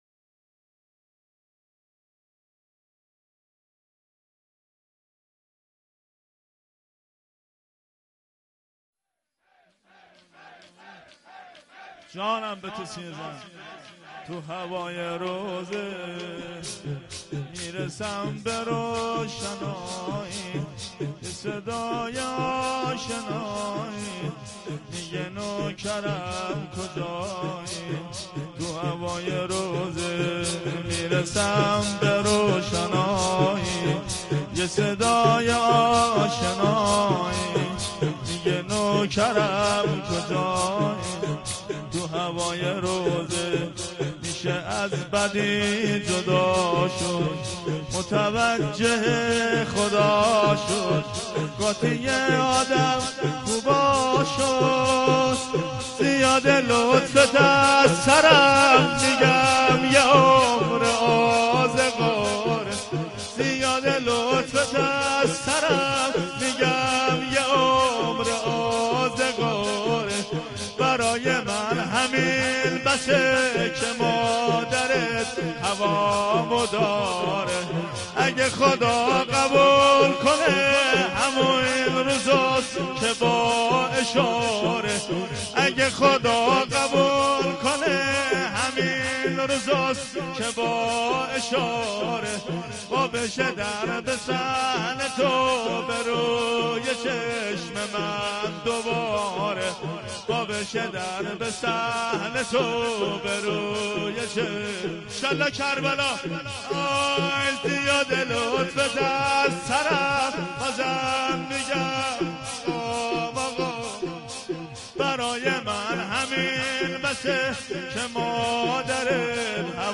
شور | تو هوای روضه می رسم به روشنایی
مداحی شور
محرم 1394 | شب چهارم